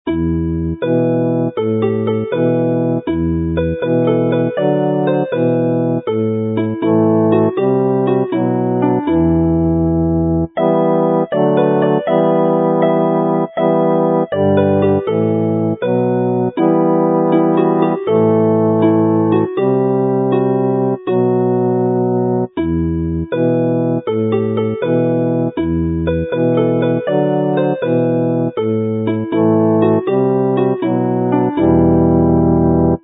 carol